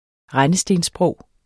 Udtale [ ˈʁanəsdens- ]